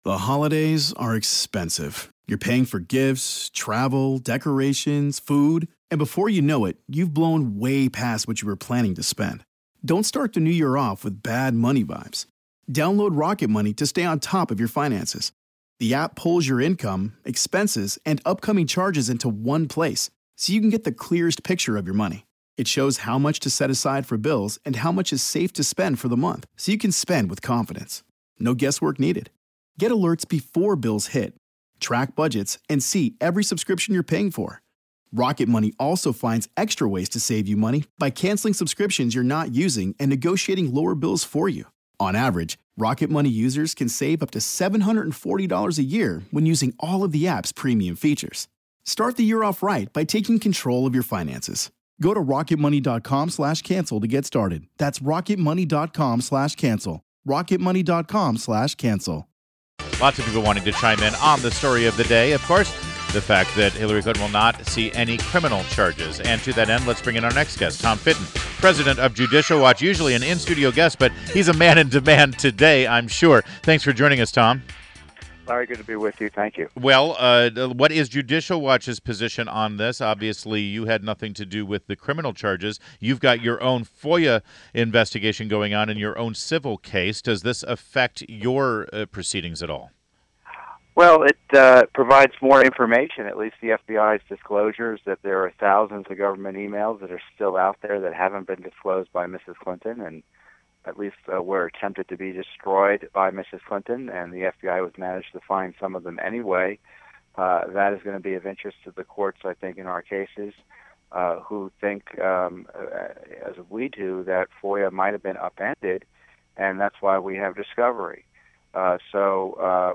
WMAL Interview Tom Fitton 07.06.16